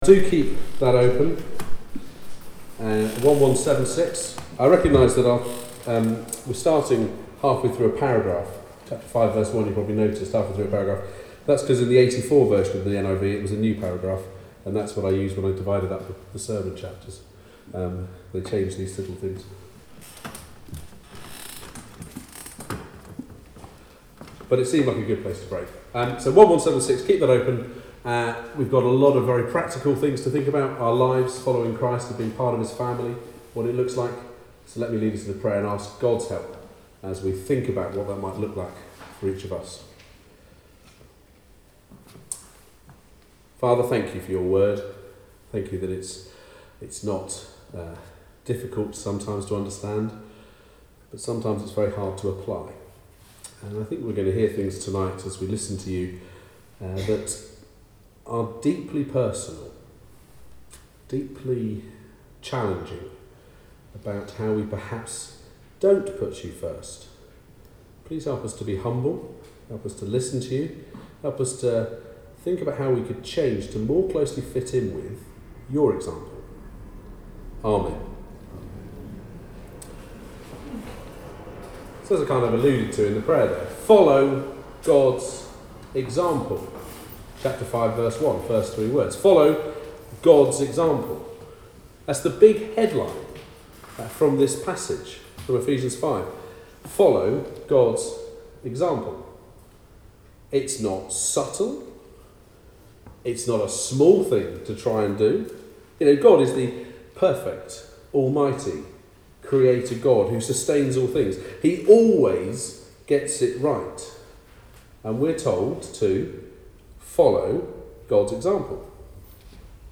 Sorry if there is some ringing in the background.
Also sorry for the squeaky chair in the background.
Service Type: Weekly Service at 4pm